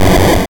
HitSFX.ogg